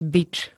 bič m. (bíš)
Zvukové nahrávky niektorých slov